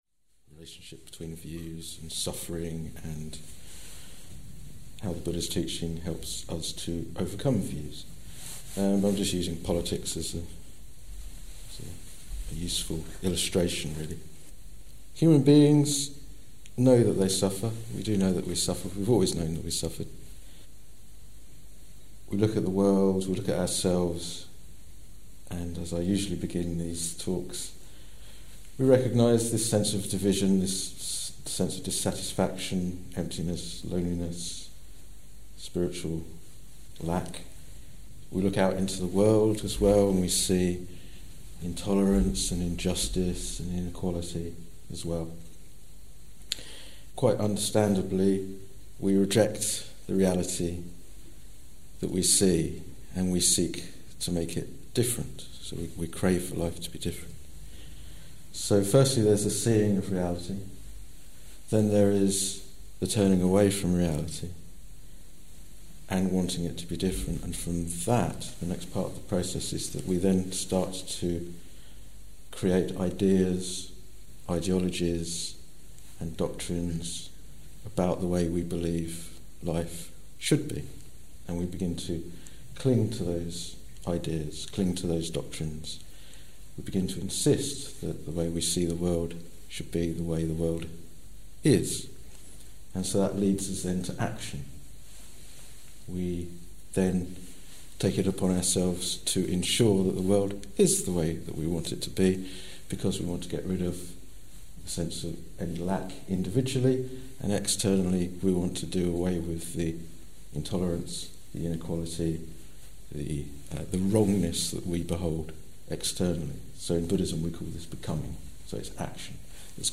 This talk was given in November 2017